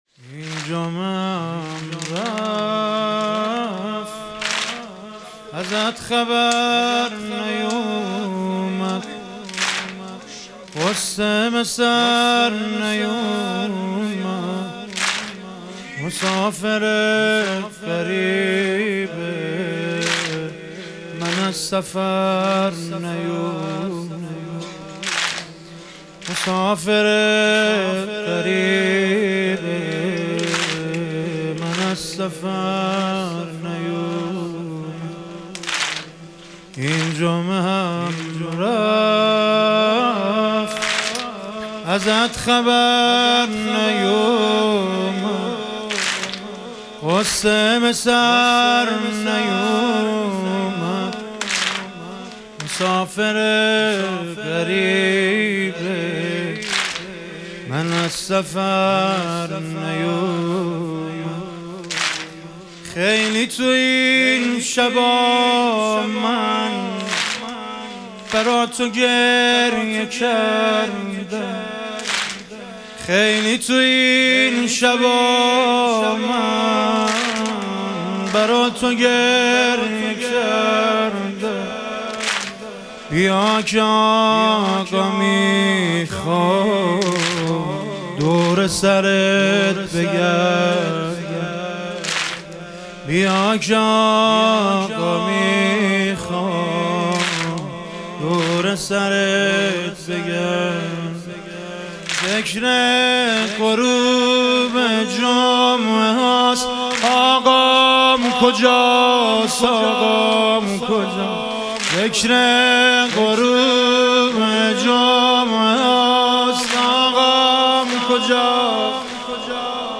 ایام فاطمیه دوم - واحد - 6 - 1403